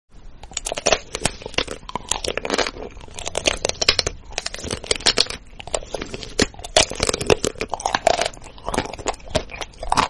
Food ASMR (Part 265) Eating sound effects free download